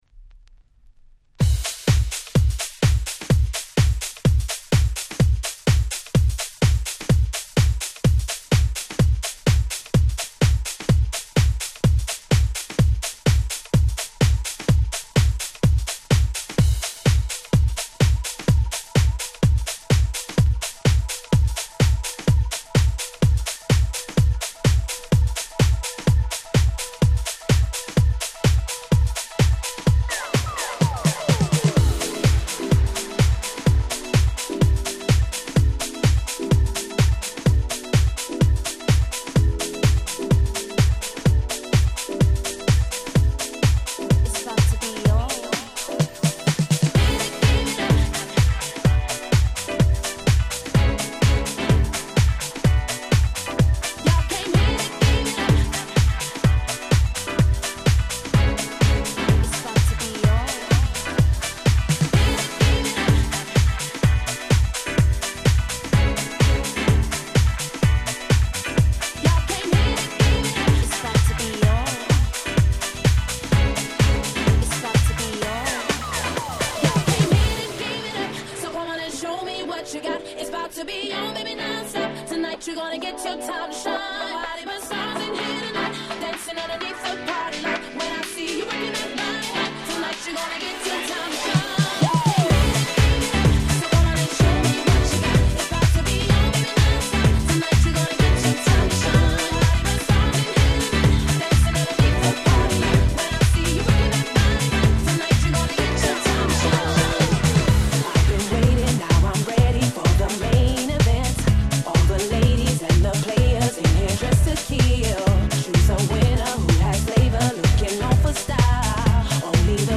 【Media】Vinyl 12'' Single
キャッチー系